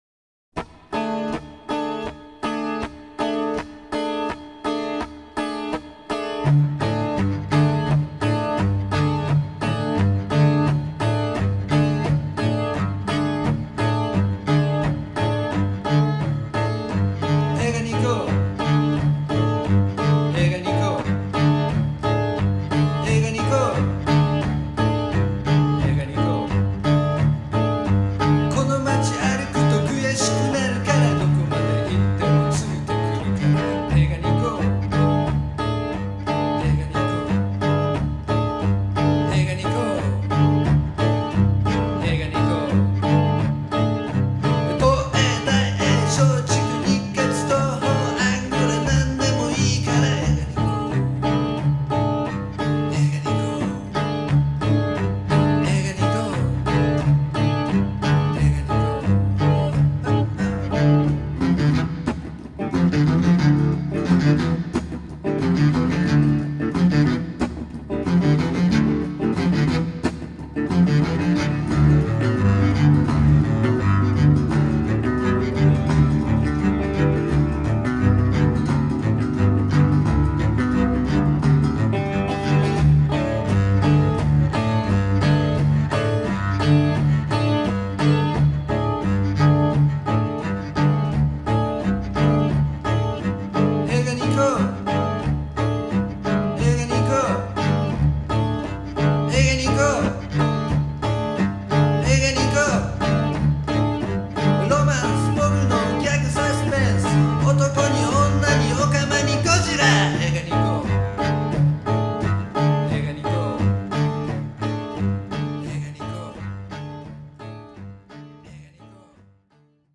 ●ヤマハの4chカセットＭＴＲによる一発録音。
チェロとギターとヴォーカルの微妙なからみと、目の醒めるような言葉の世界は、まさに前代未聞である。